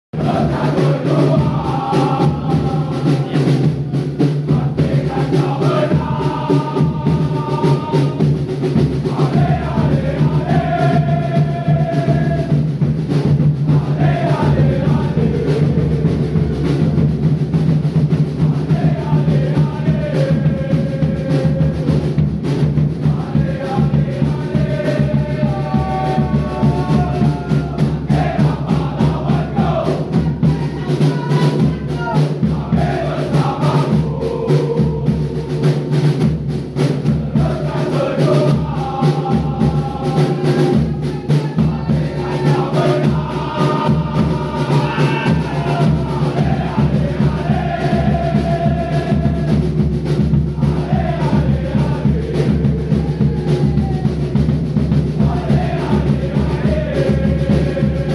elephant army at sukpa kuantan stadium MPFL 2025 PRFC 6-7 JDT elephant army vs boys of straits